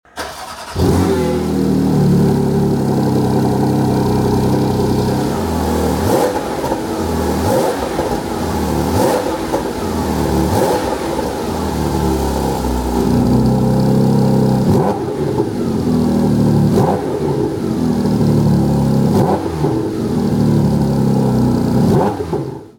Listen to that symphony!
• Titanium Exhaust Tailpipes
• 4.0L Flat-6 Naturally Aspirated Petrol Engine
Revs.mp3